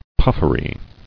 [puff·er·y]